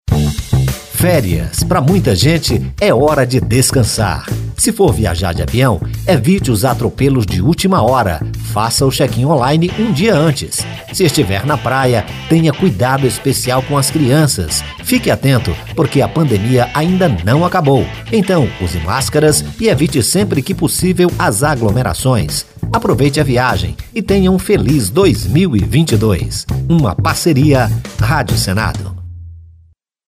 Preparamos dois spots com dicas para quem vai sair de viagem nessas férias de verão.